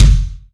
Index of /kb6/Akai_MPC500/1. Kits/Garage Kit
grg big kick.WAV